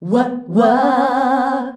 UAH-UAAH C.wav